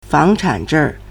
房产证 (房產證) fángchǎn zhèng
fang2chan3zheng4.mp3